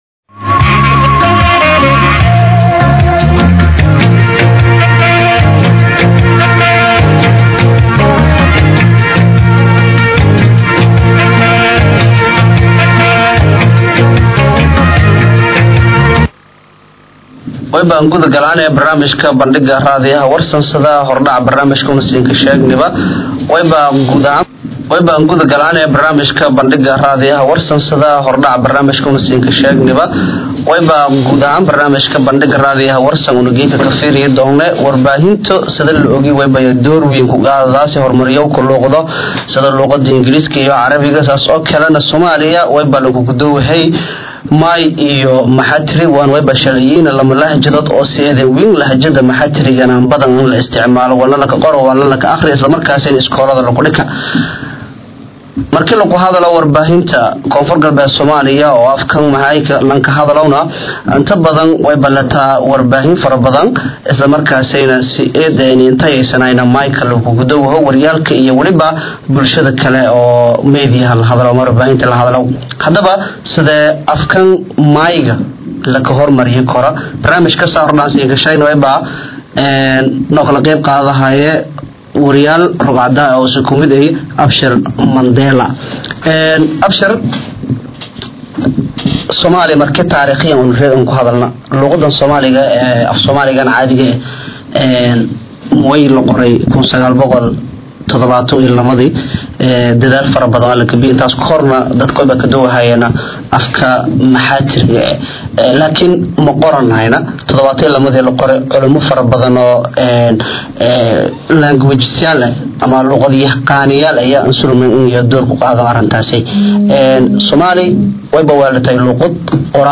Dhageyoo: Barnaamij Dood Wadaag ah Sidee lagu Horumarinta Karaa Luuqada Maayga ?
Dooda waxaa Qabatey Idaacada Warsan Ee Magaalada Baydhabo